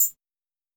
S 78_Shaker.wav